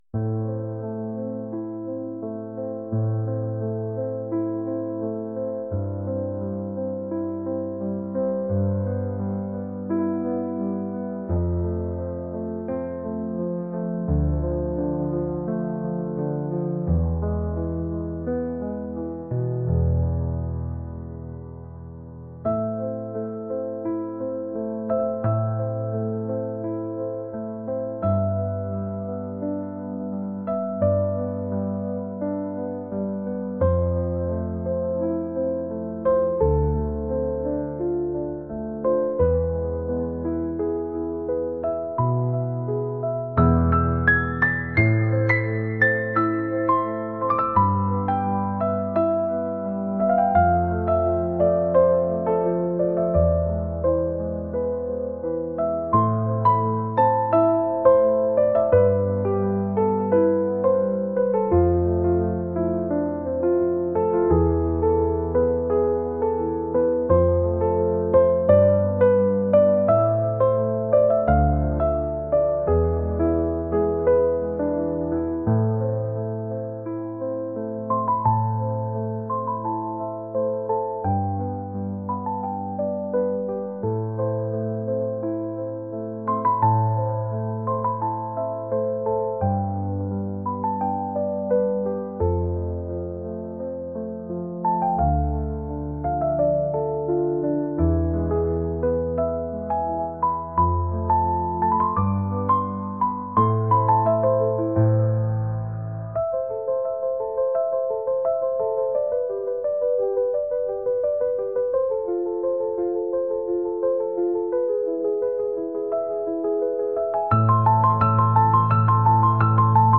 ambient | pop | cinematic